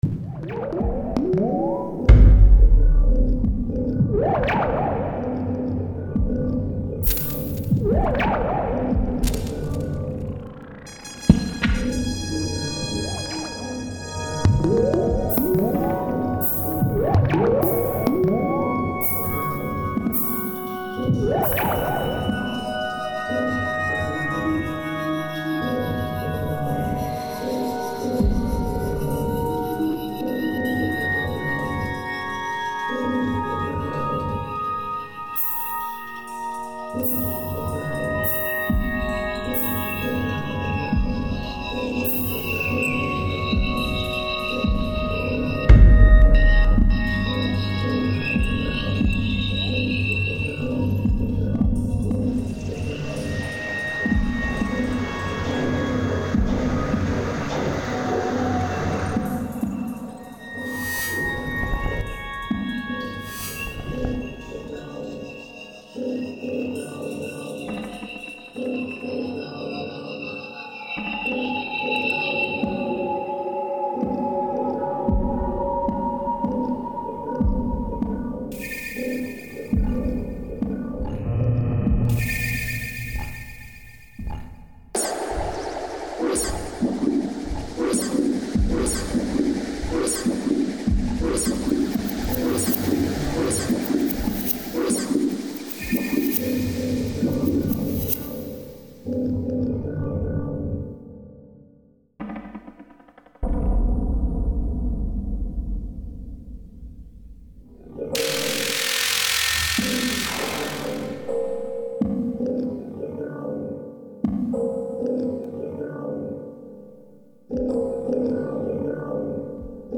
These samples were recorded directly from eden while running.